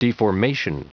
Prononciation du mot deformation en anglais (fichier audio)
Prononciation du mot : deformation